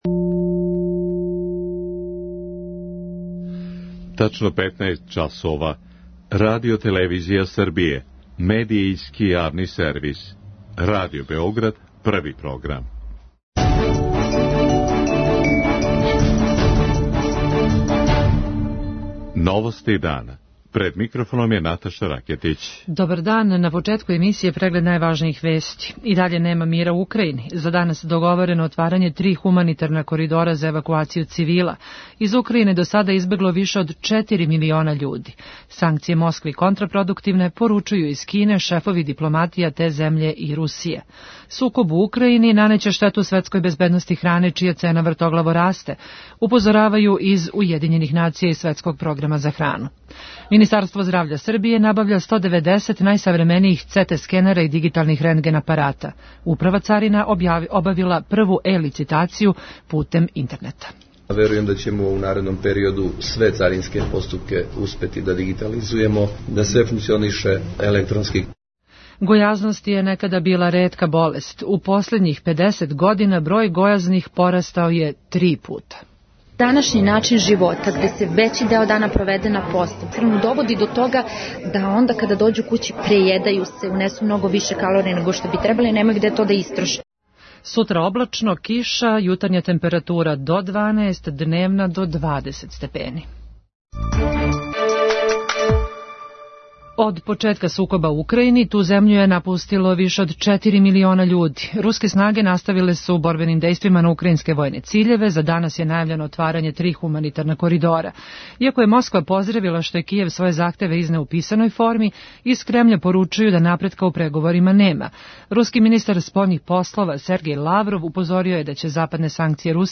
novosti3003.mp3